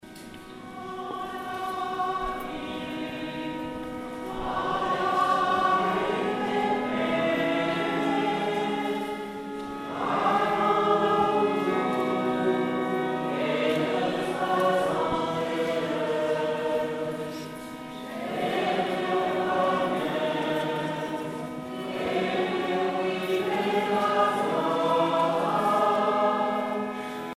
prière, cantique
Pièce musicale éditée